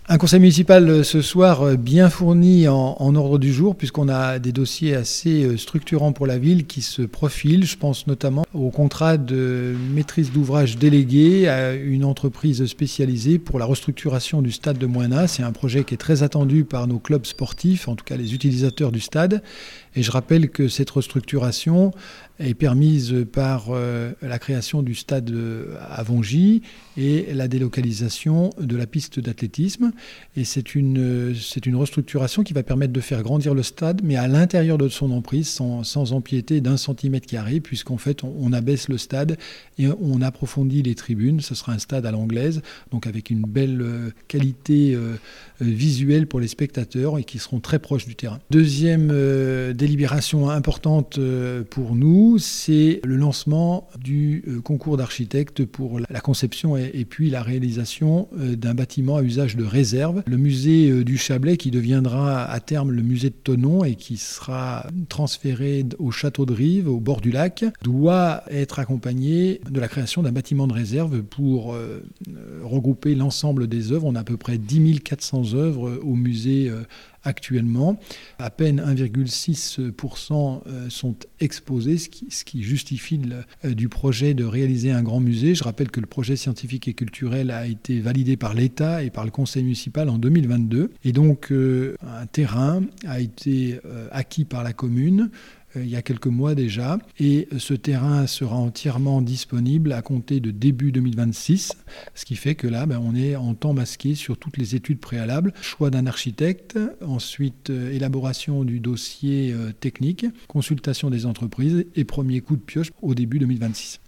Thonon-les-Bains : un conseil municipal ce soir (lundi 19 février) - interview
Christophe Arminjon, Maire de Thonon.